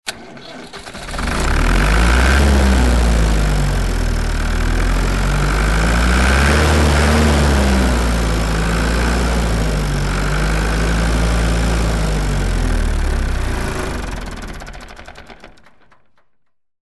Запуск мотора трактора: звук заводки и глохнет